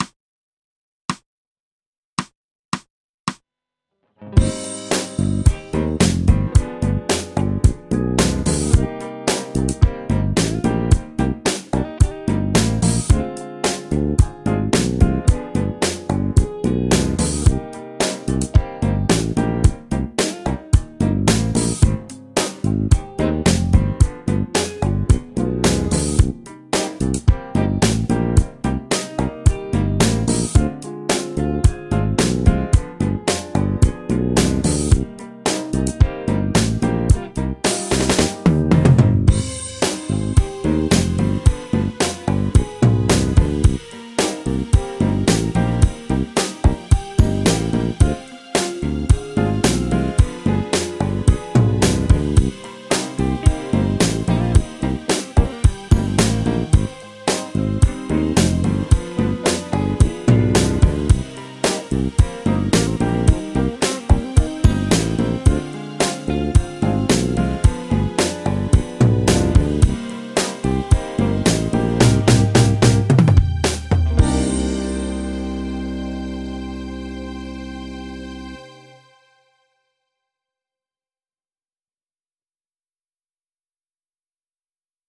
コンビネーション・オブ・ディミニッシュ・スケール ギタースケールハンドブック -島村楽器